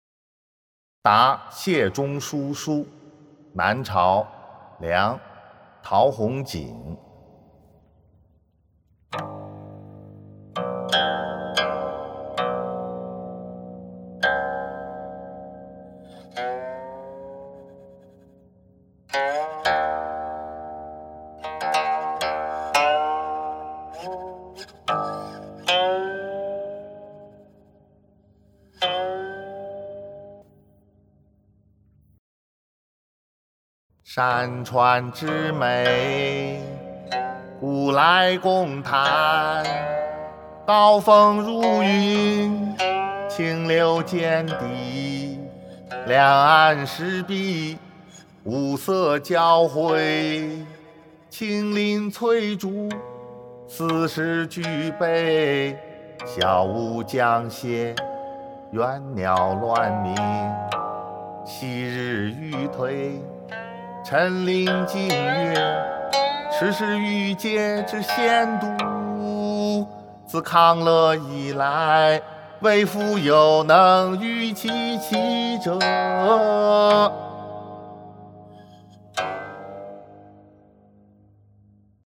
［南朝·梁］陶弘景 《答谢中书书》（吟咏）